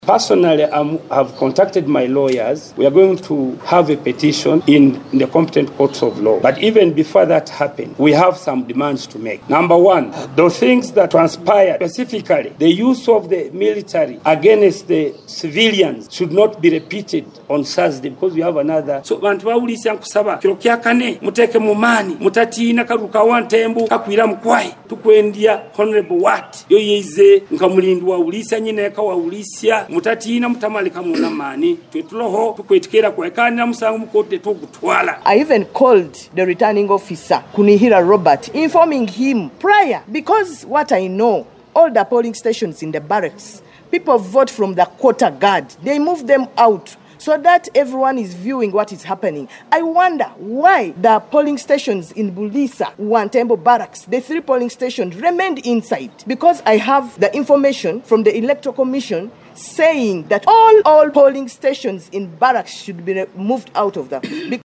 This was revealed during their joint press briefing at Hoima Kolping gardens yesterday.